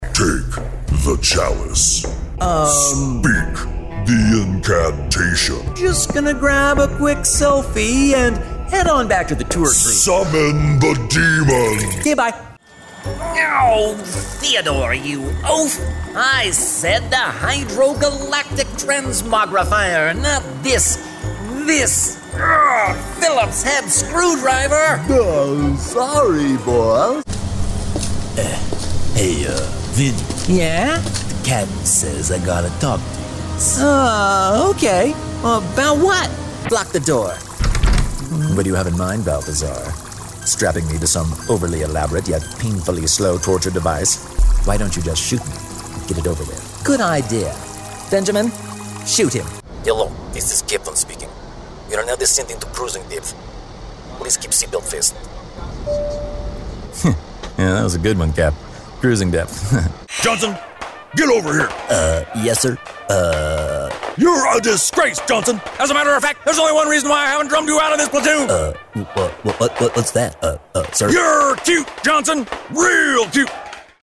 Versatile North American male as at home with character acting as corporate narration. First class home studio.
Character Demo